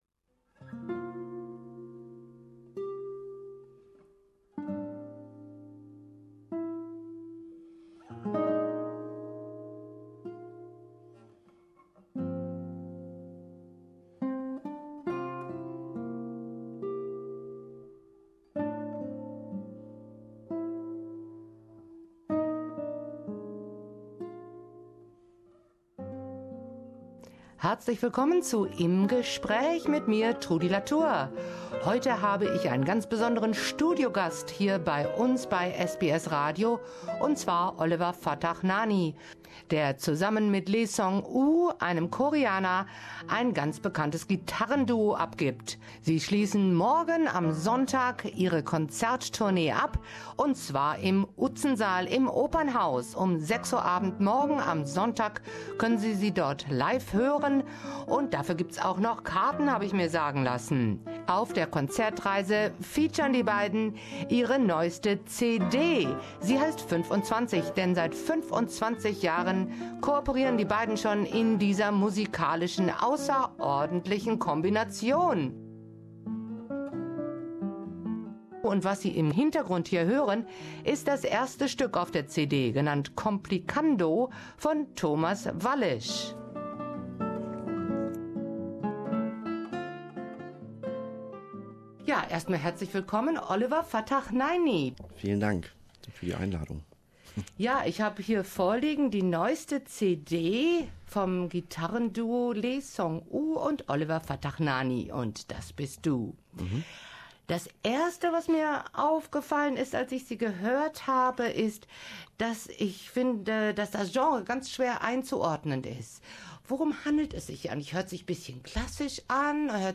Im Gespräch
im SBS studio